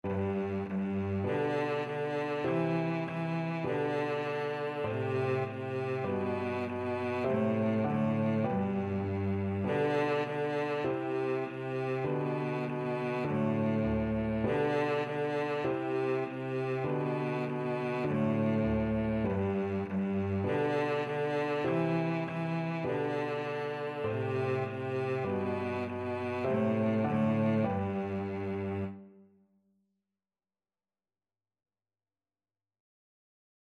Cello
Moderato
4/4 (View more 4/4 Music)
Traditional (View more Traditional Cello Music)